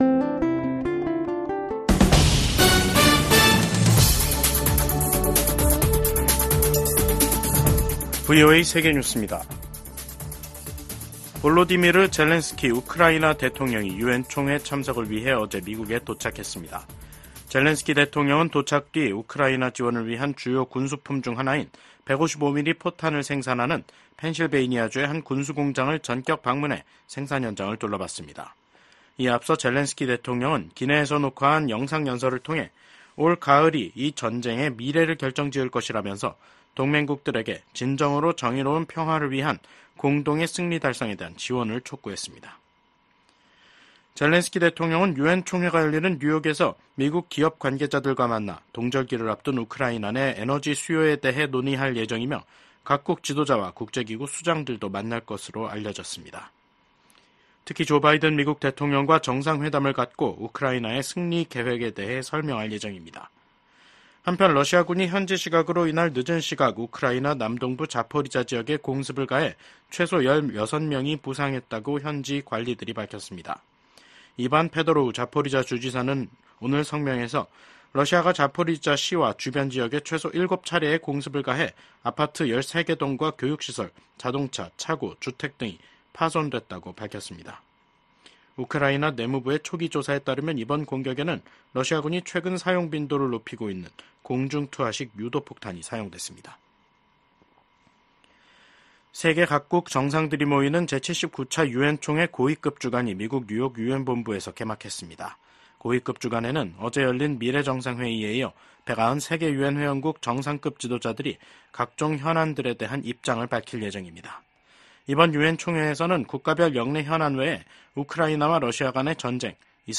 VOA 한국어 간판 뉴스 프로그램 '뉴스 투데이', 2024년 9월 20일 2부 방송입니다. 미국과 일본, 호주, 인도 정상들이 북한의 미사일 발사와 핵무기 추구를 규탄했습니다. 미국 정부가 전쟁포로 실종자 인식의 날을 맞아 미군 참전용사를 반드시 가족의 품으로 돌려보낼 것이라고 강조했습니다. 유엔 북한인권특별보고관이 주민에 대한 통제 강화 등 북한의 인권 실태가 더욱 열악해지고 있다는 평가를 냈습니다.